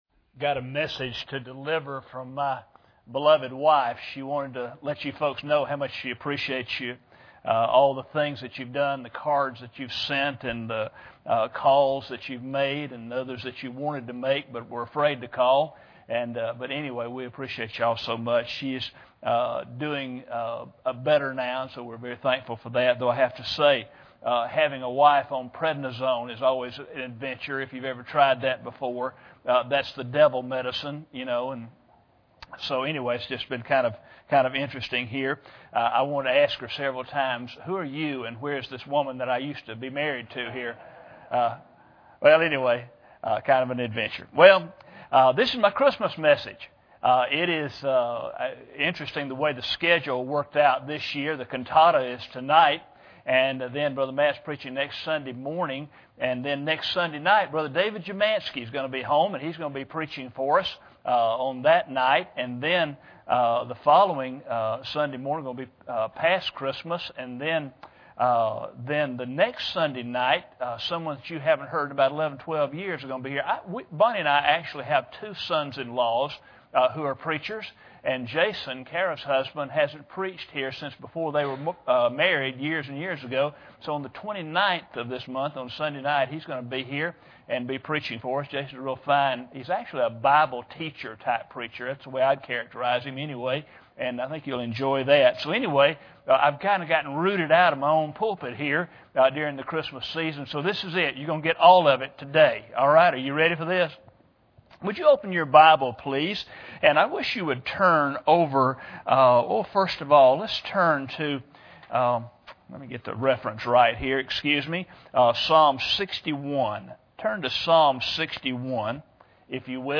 Psalm 61:2 Service Type: Sunday Morning Bible Text